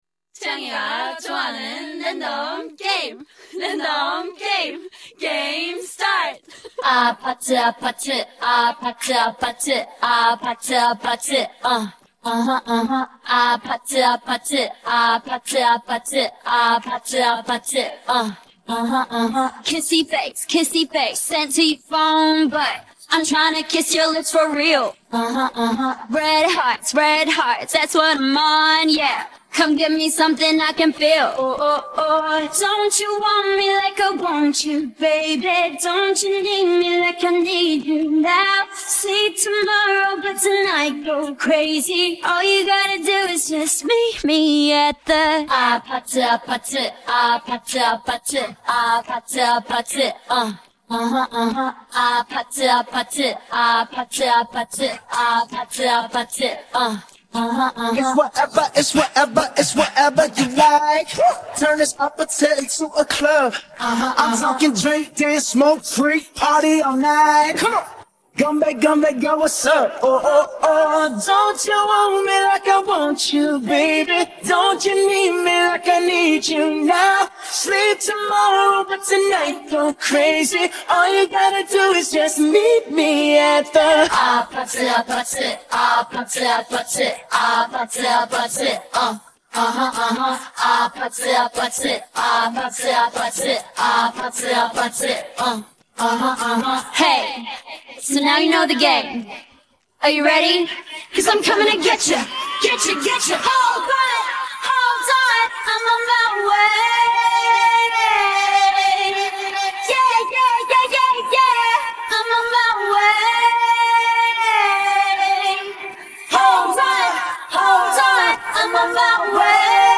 Parte Vocal